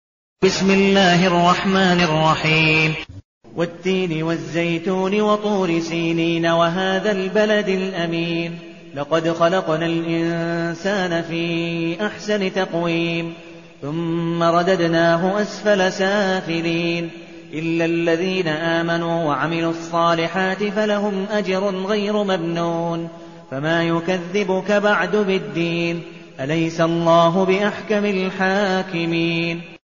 المكان: المسجد النبوي الشيخ: عبدالودود بن مقبول حنيف عبدالودود بن مقبول حنيف التين